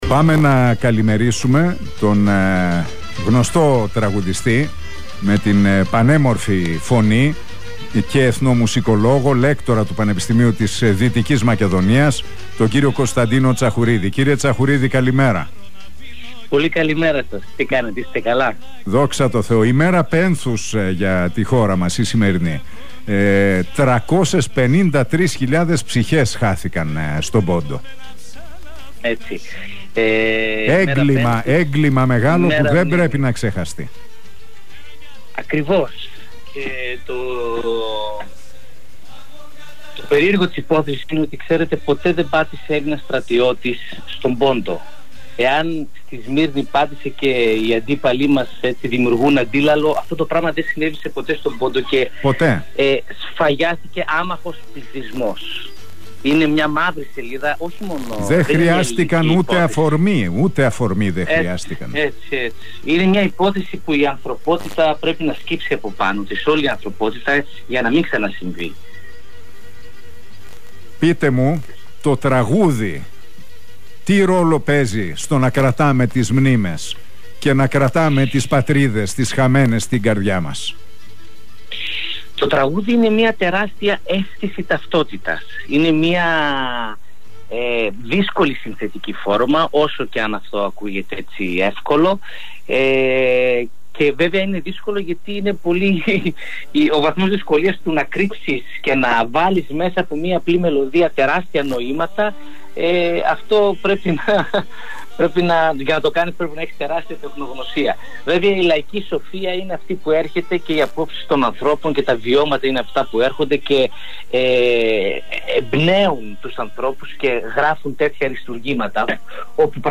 μιλώντας στον Realfm 97,8 και τον Νίκο Χατζηνικολάου.